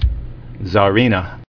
[tsa·ri·na]